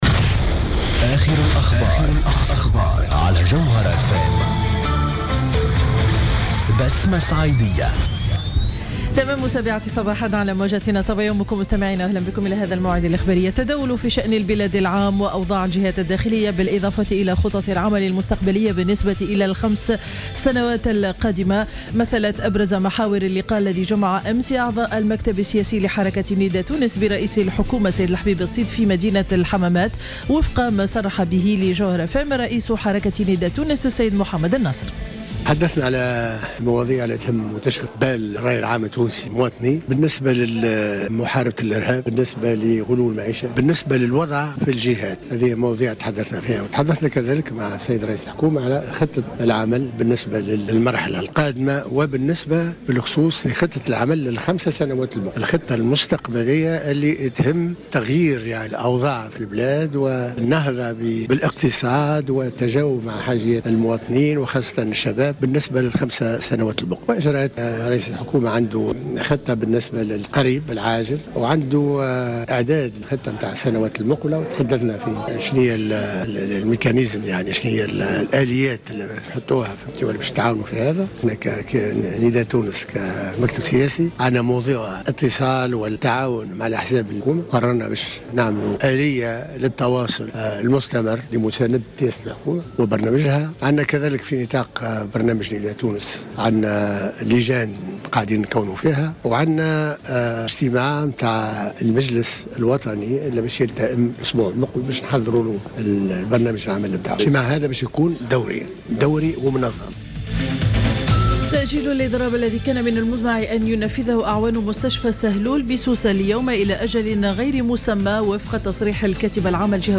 نشرة أخبار السابعة صباحا ليوم الاثنين 20 أفريل 2015